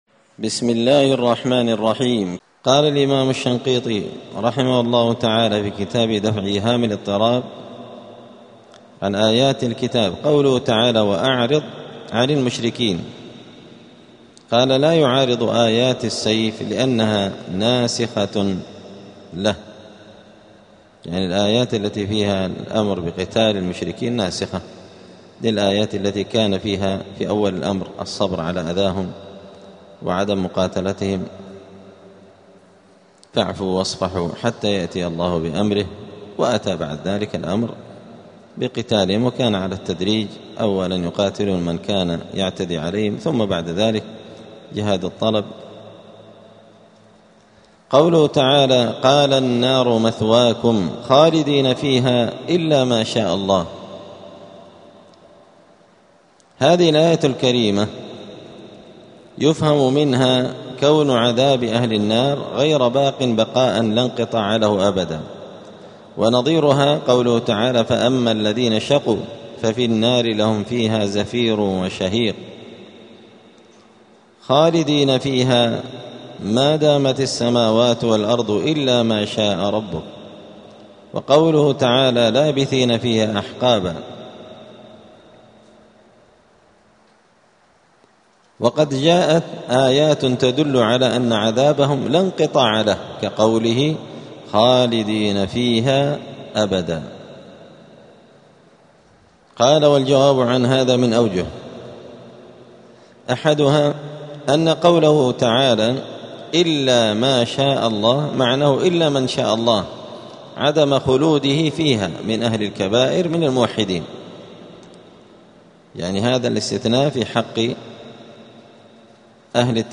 *الدرس الثامن والثلاثون (38) {سورة الأنعام}.*
دار الحديث السلفية بمسجد الفرقان قشن المهرة اليمن